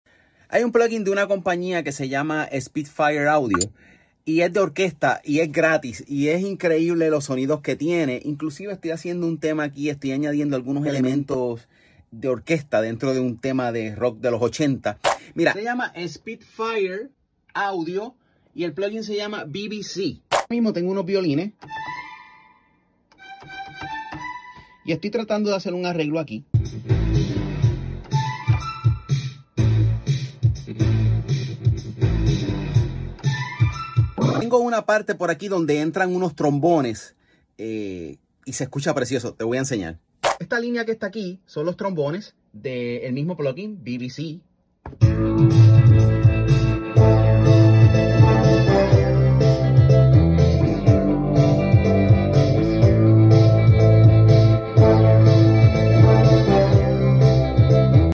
Orchestra Sound ¡Gratis!